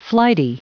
Prononciation audio / Fichier audio de FLIGHTY en anglais
Prononciation du mot : flighty